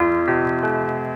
keys_05.wav